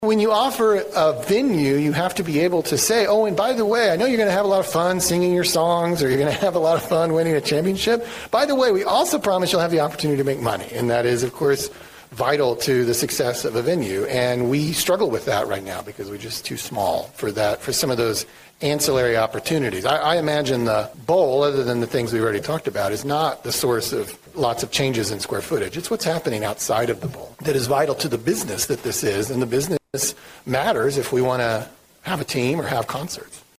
Coming off of an NBA title, Oklahoma City Mayor David Holt held a press conference on Wednesday and released renderings of what the new arena will look like for the home of the Thunder.